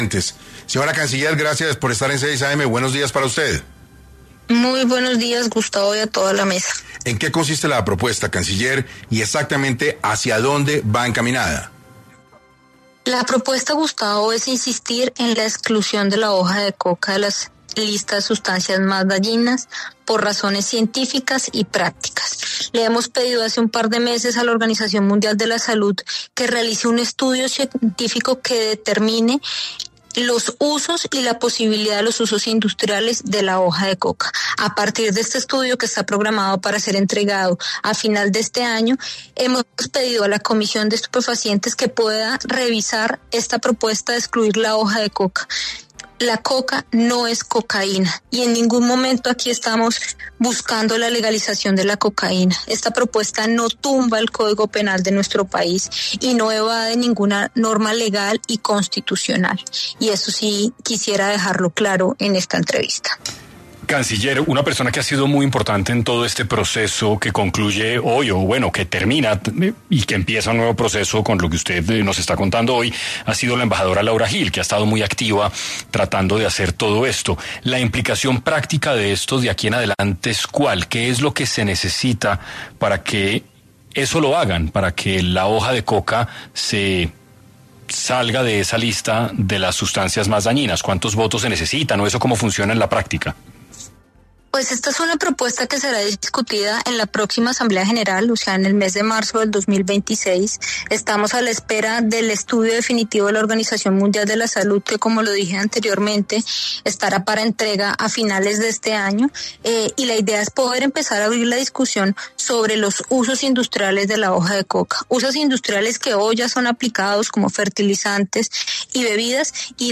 En entrevista con 6AM de Caracol Radio, Sarabia aseguró que pese a los esfuerzos del país contra el narcotráfico, la estrategia actual no ha logrado frenar el consumo, la producción y el tráfico de drogas.